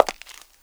PAVEMENT 2.WAV